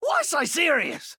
Download Pk Overwatch Junkrat Why So Serious sound effect for free.